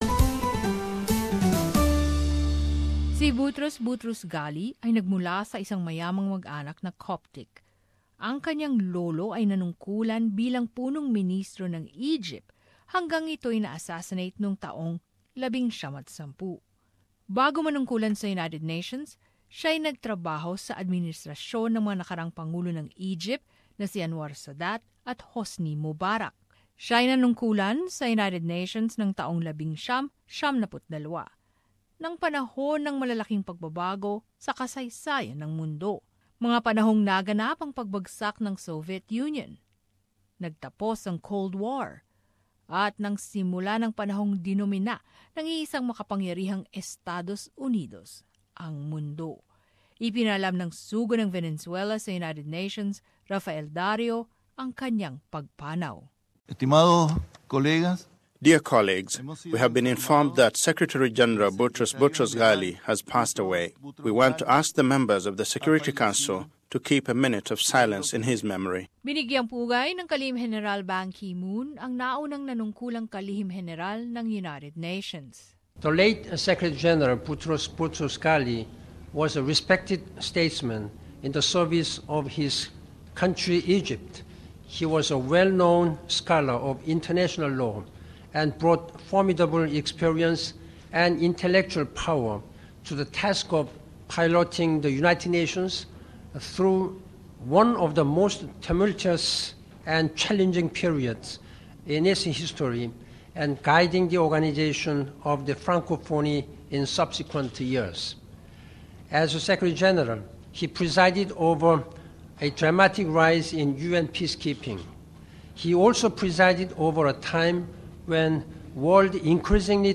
As this report shows, he was also one of the most controversial figures in U-N history.